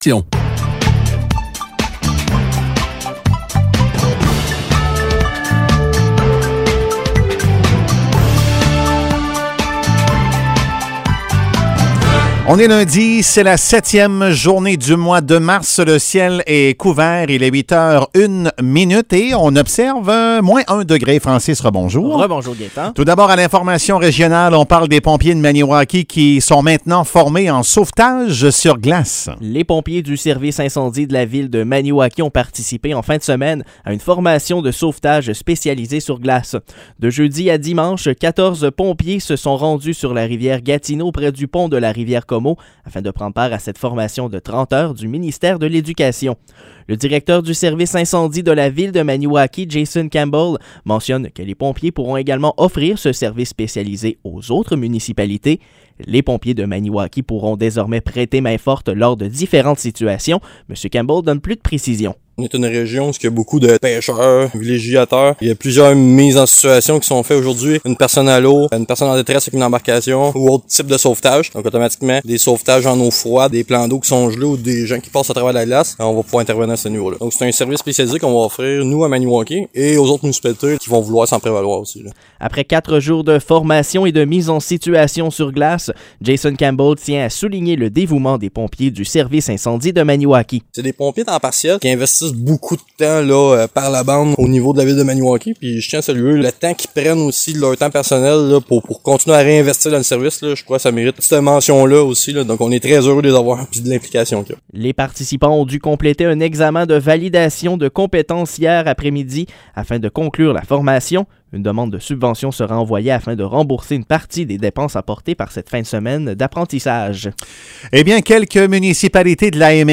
Nouvelles locales - 7 mars 2022 - 8 h